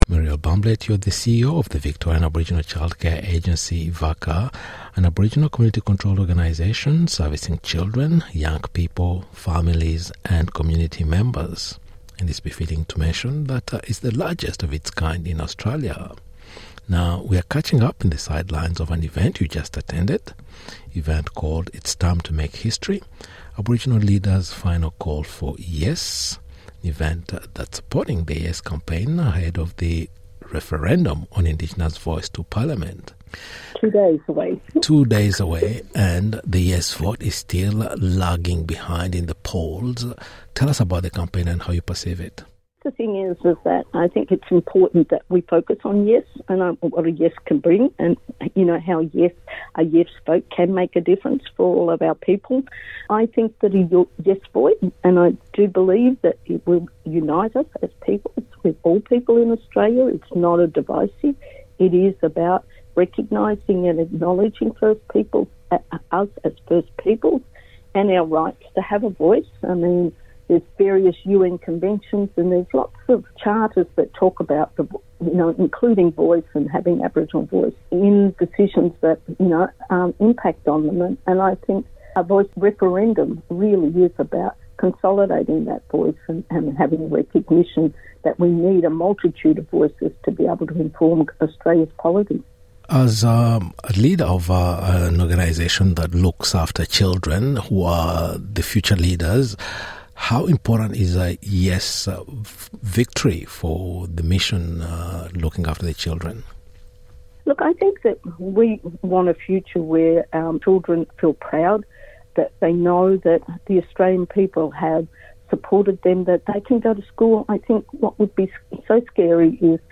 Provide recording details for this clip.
in the sidelines of a large gathering of Aboriginal leaders and allies from across Victoria.